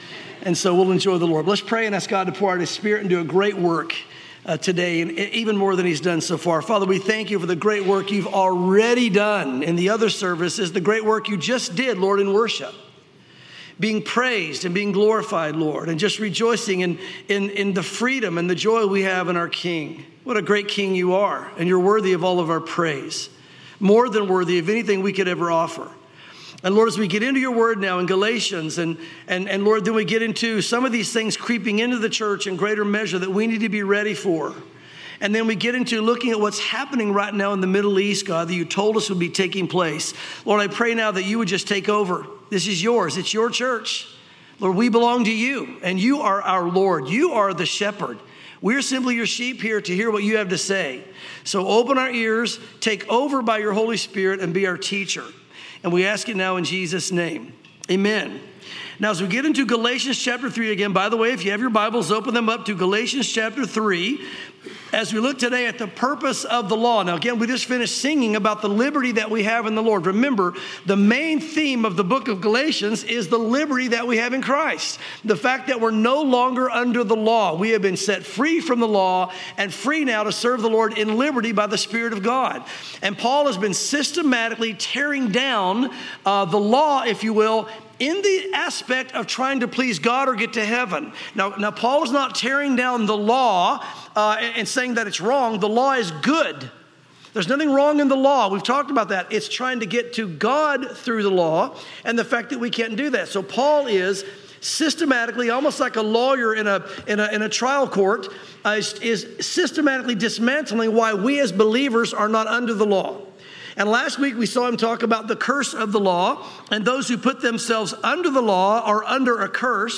sermons Galatians 3:19-29 | The Purpose of the Law